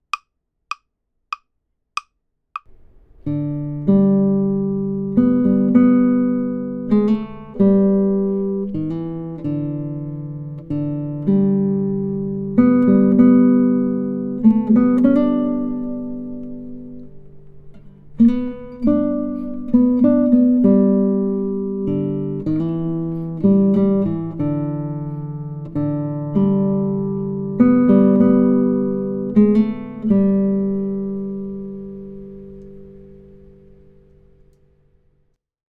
Aim for a tempo of about 96 BPM (andante).
Amazing Grace | Melody only